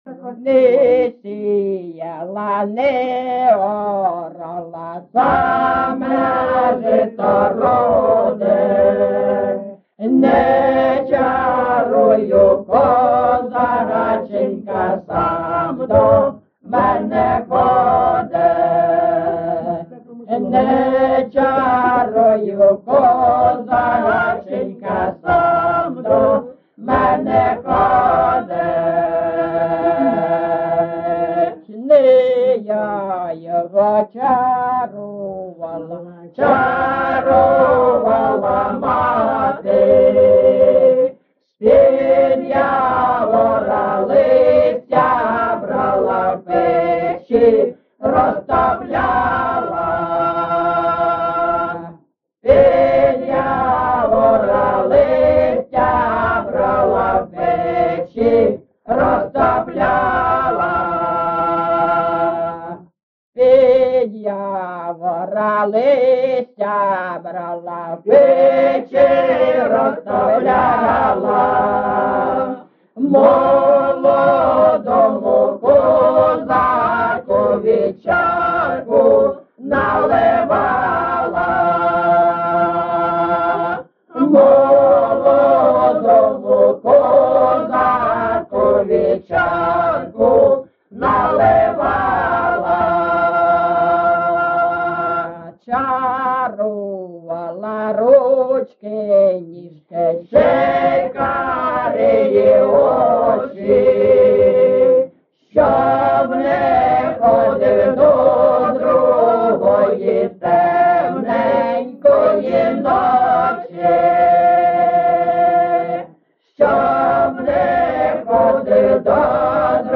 GenrePersonal and Family Life
Recording locationLyman, Zmiivskyi (Chuhuivskyi) District, Kharkiv obl., Ukraine, Sloboda Ukraine
People's amateur folklore group "Kalynonka" Lyman SBK